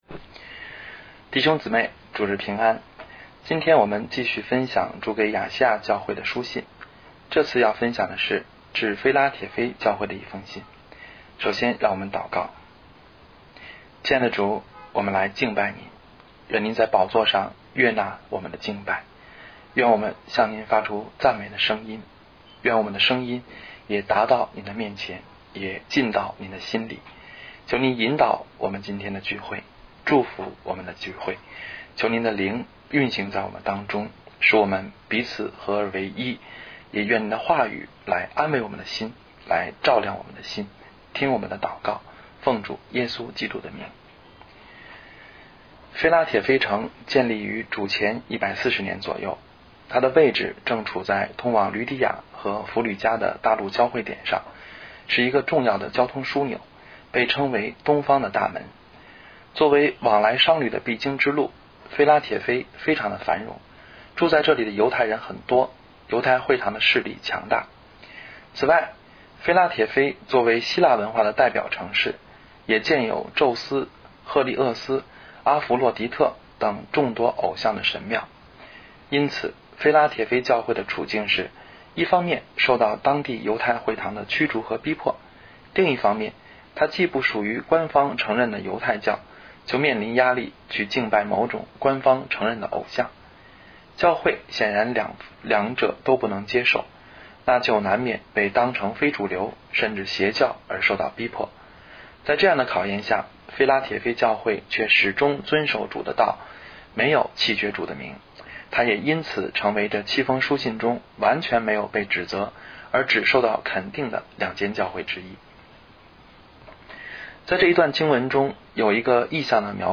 祂开的门无人能关——2013年1月20日讲章